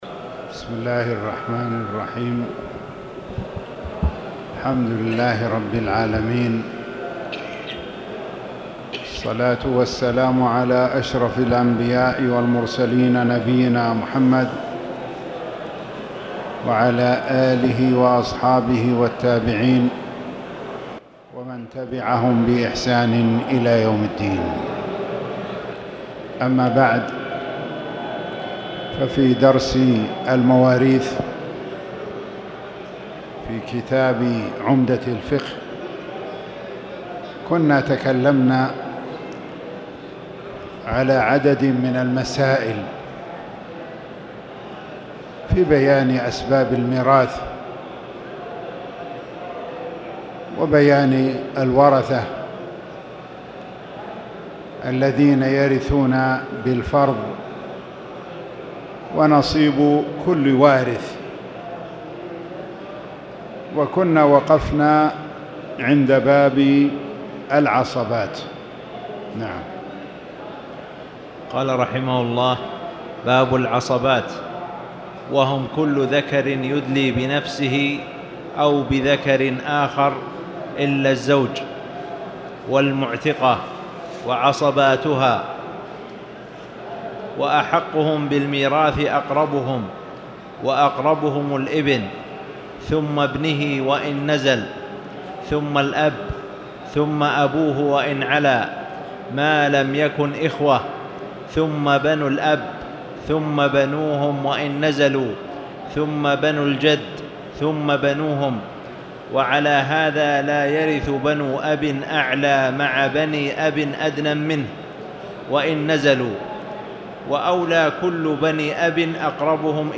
تاريخ النشر ١٩ صفر ١٤٣٨ هـ المكان: المسجد الحرام الشيخ